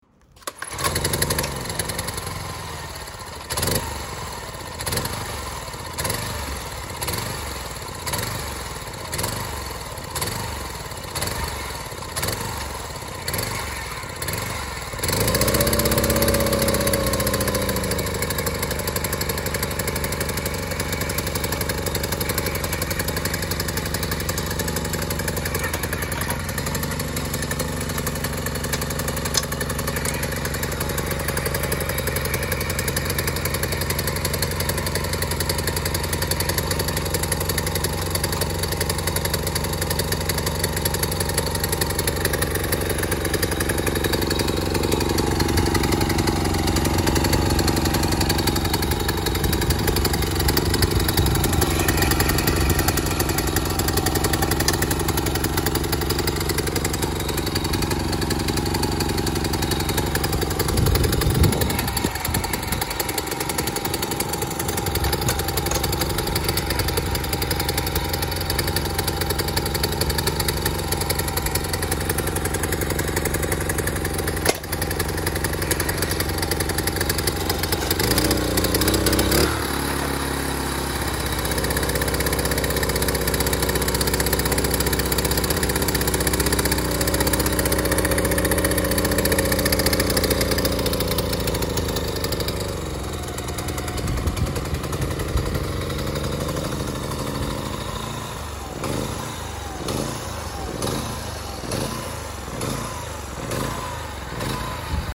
Звук бензинового мотокультиватора: запуск, холостой ход, переключение передач